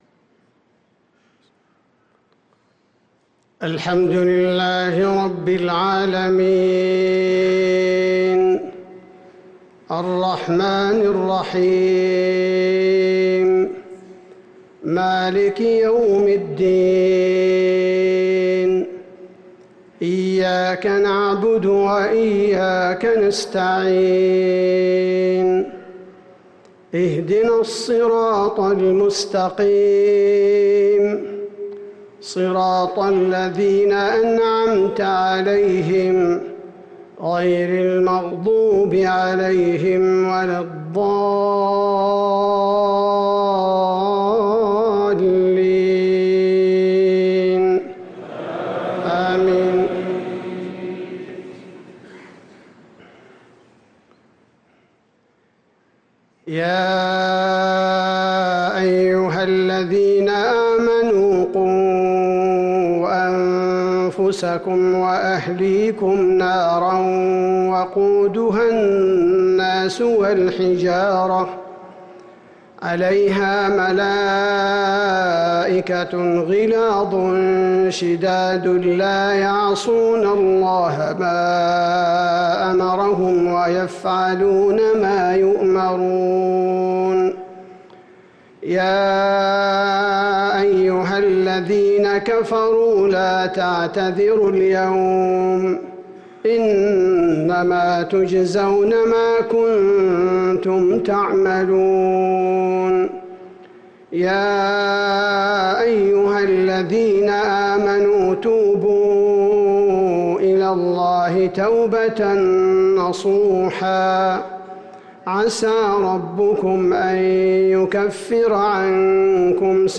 صلاة العشاء للقارئ عبدالباري الثبيتي 26 جمادي الأول 1445 هـ
تِلَاوَات الْحَرَمَيْن .